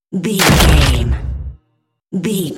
Dramatic deep hit bloody
Sound Effects
heavy
intense
dark
aggressive
hits